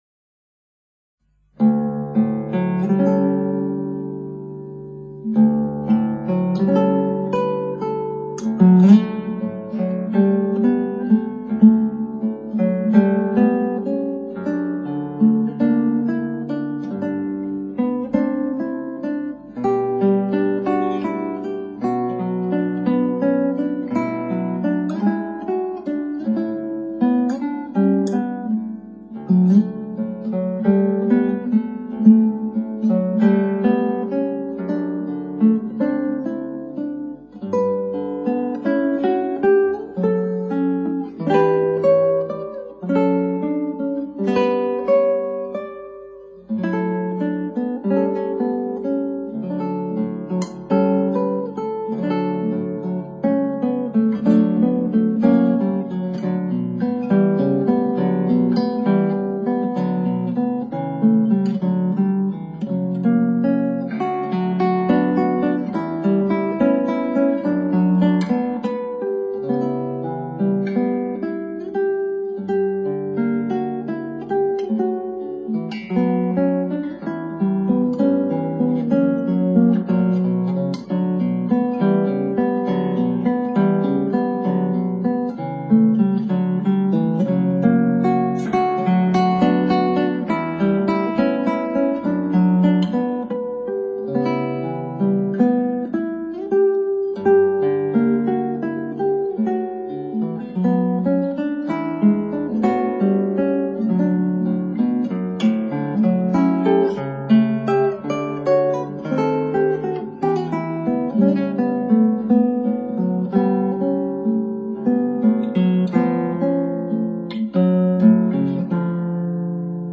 クラシックギター　ストリーミング　コンサートサイト